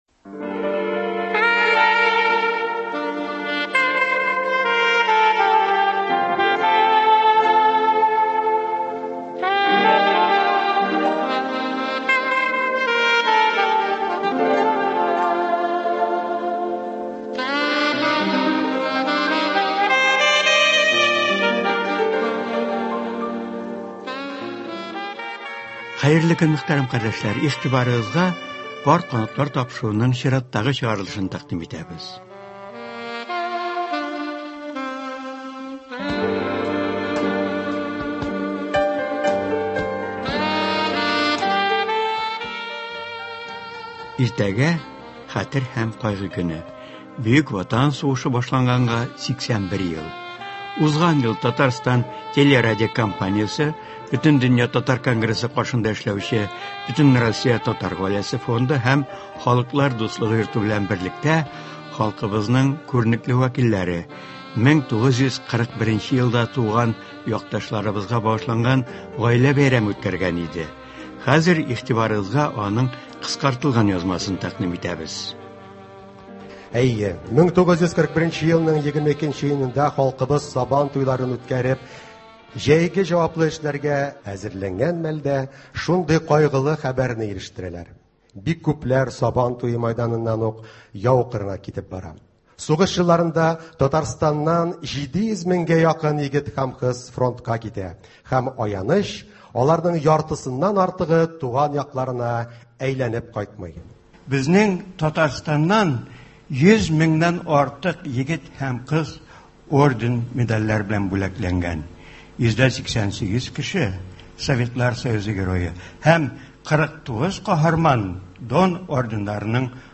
Татарстан дәүләт телерадиокомпаниясе Бөтендөнья татар конгрессы, Бөтенроссия татар гаиләсе фонды белән берлектә Бөек Ватан сугышы башлануга 80 ел тулган көнне сәхнәдән “Пар канатлар” тапшыруының махсус чыгарылышын үткәргән иде. Тапшыруда бу кичәнең 1 нче өлеше кабатлап бирелә.